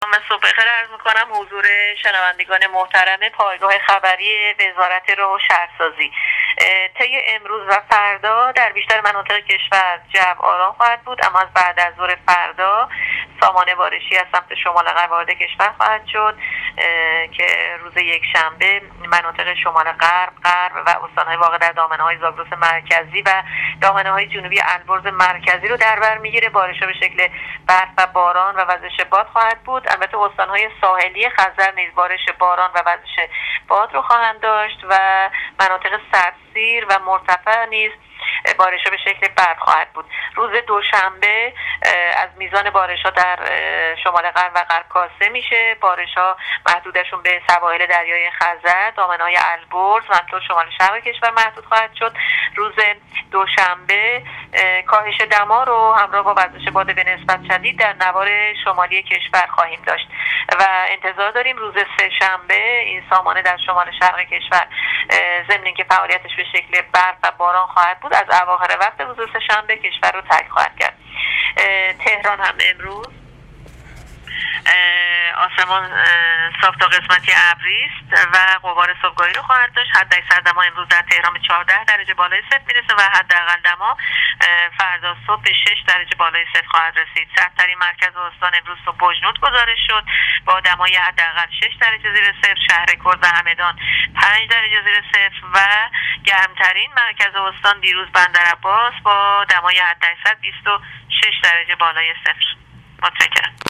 گزارش رادیو اینترنتی از آخرین وضعیت آب و هوای ۹ اسفندماه ۱۳۹۸